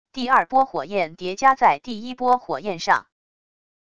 第二波火焰叠加在第一波火焰上wav音频